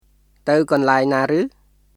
[タウ・コンラエン・ナー・ルー　tə̀w kɔnlaeŋ naː rɯː]